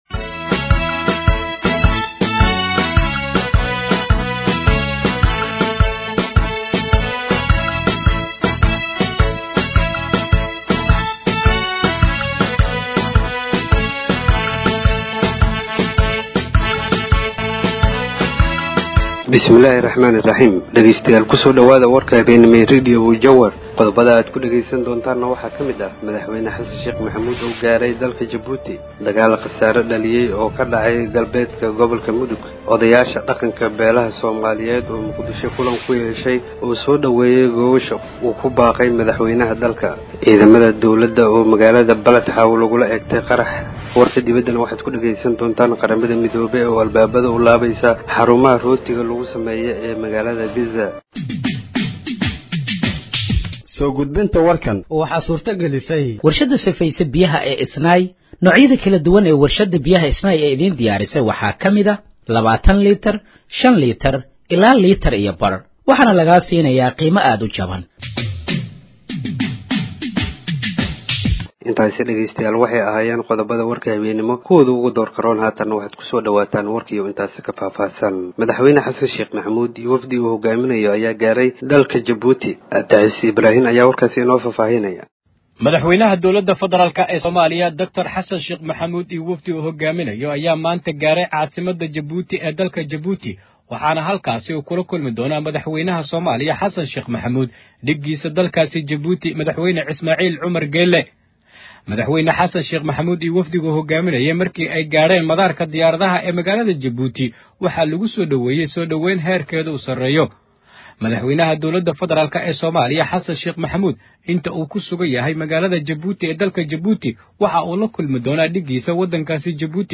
Dhageeyso Warka Habeenimo ee Radiojowhar 02/04/2025